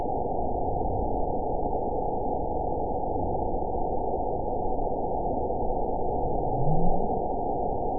event 921783 date 12/19/24 time 01:51:45 GMT (1 year ago) score 9.45 location TSS-AB02 detected by nrw target species NRW annotations +NRW Spectrogram: Frequency (kHz) vs. Time (s) audio not available .wav